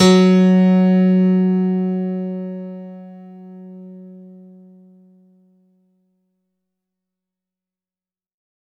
F#2  DANCE-L.wav